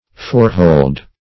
forehold - definition of forehold - synonyms, pronunciation, spelling from Free Dictionary Search Result for " forehold" : The Collaborative International Dictionary of English v.0.48: Forehold \Fore"hold`\, n. (Naut.) The forward part of the hold of a ship.